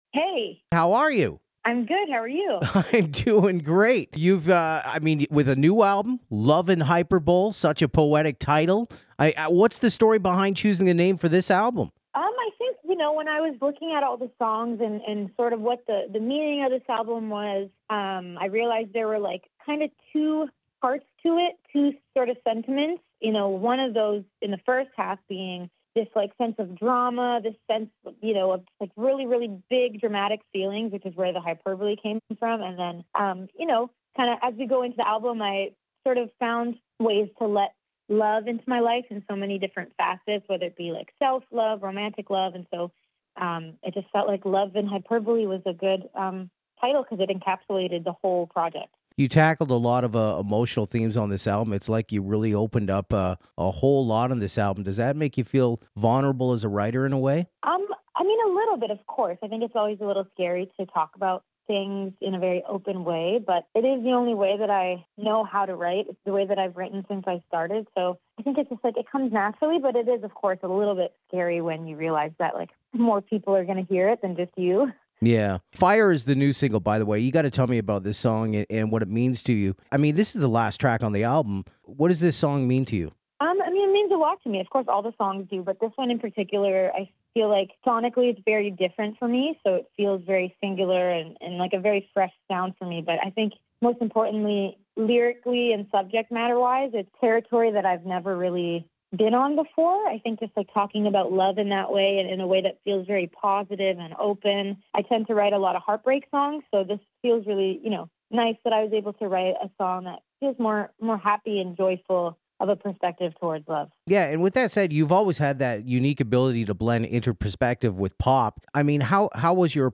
alessia-cara-interview.mp3